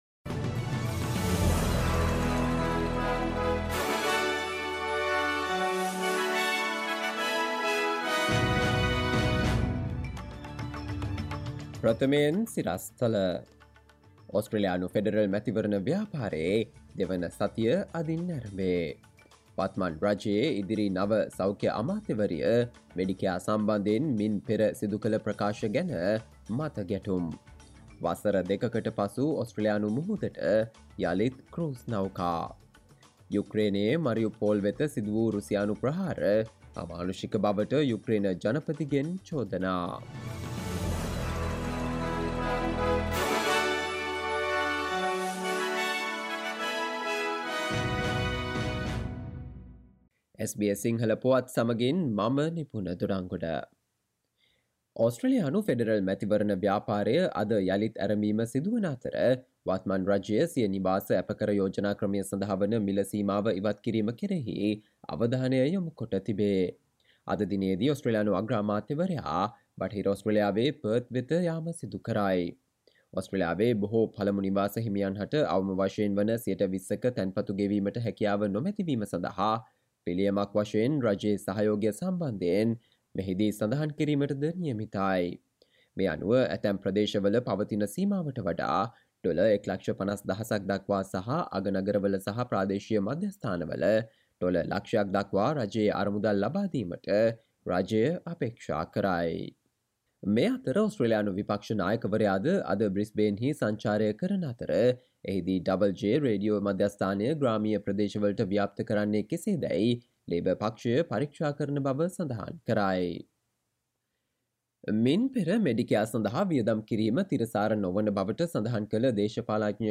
සවන්දෙන්න 2022 අප්‍රේල් 18 වන සඳුදා SBS සිංහල ගුවන්විදුලියේ ප්‍රවෘත්ති ප්‍රකාශයට...
sinhala_news_april18.mp3